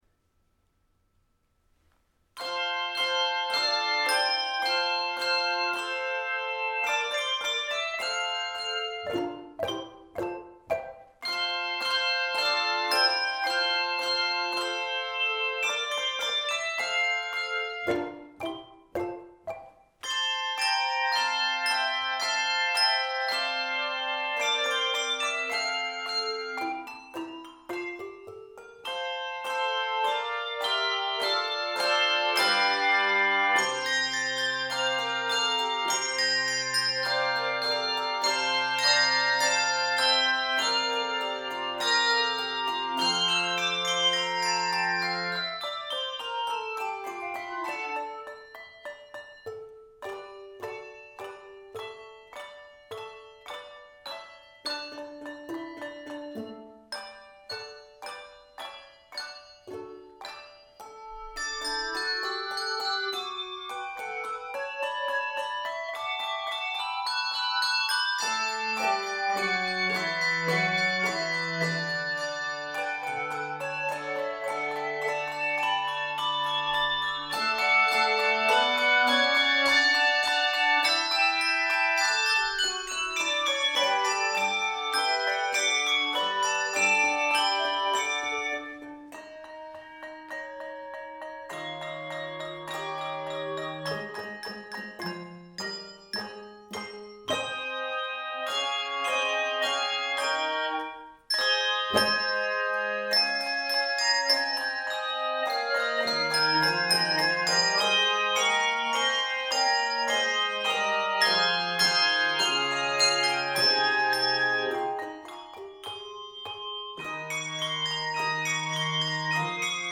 lively and fantasia-like setting
Keys of F Major, D Major, and Ab Major.